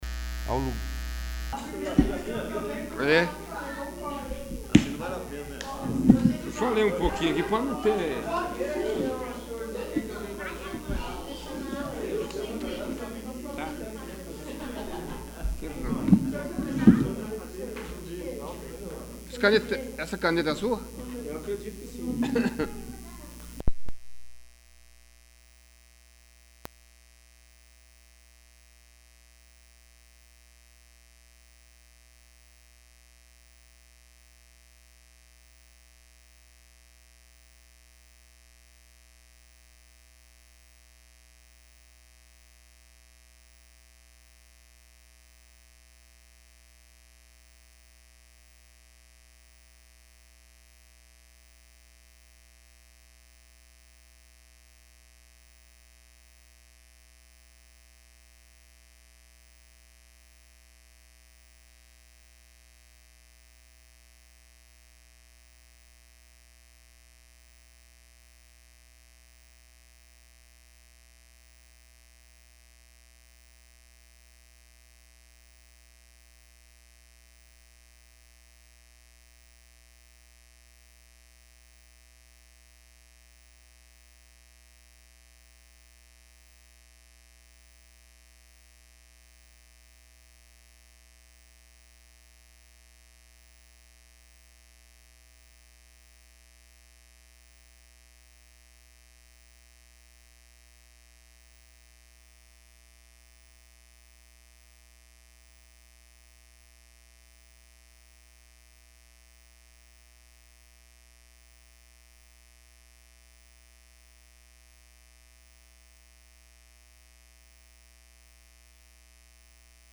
21º. Sessão Ordinária